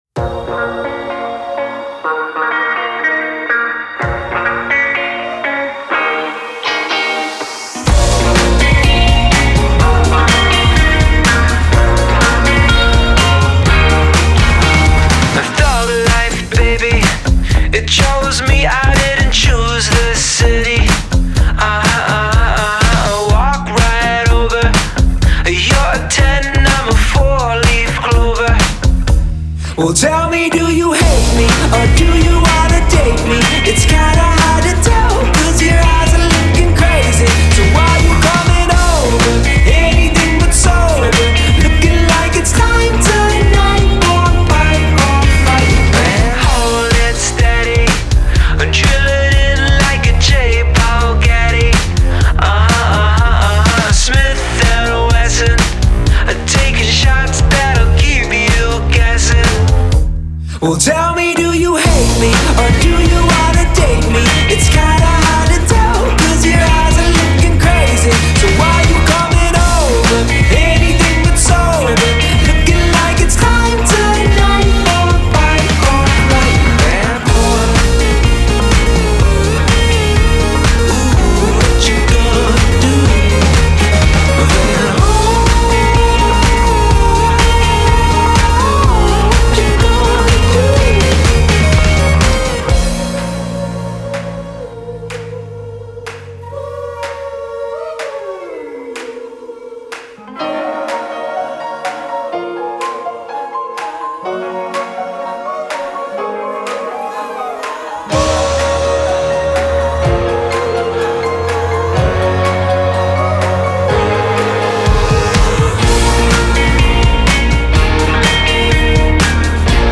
a chorus that makes for a pretty enjoyable sing-along